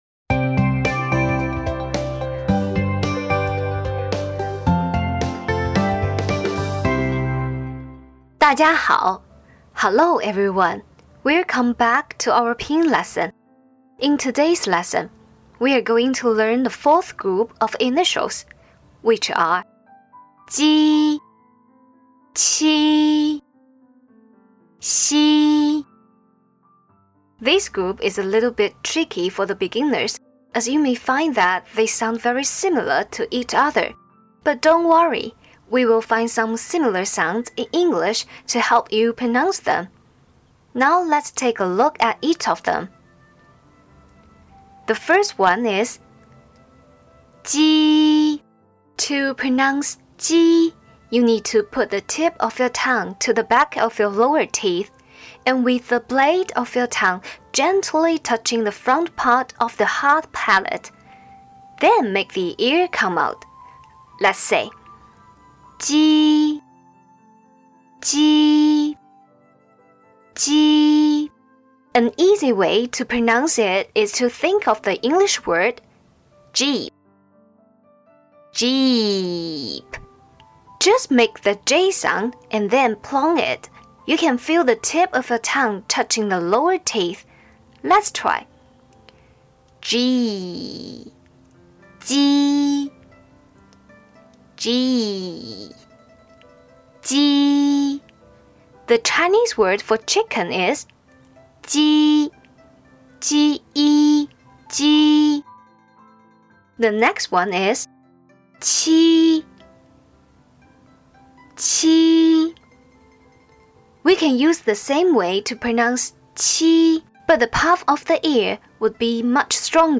Lesson Audio Review Download Audio Lesson (mp3 version) Lecture Notes (pdf version)